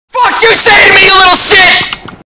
Play Ninja H1z1 Rage - SoundBoardGuy
ninja-h1z1-rage.mp3